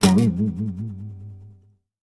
Звуки гитары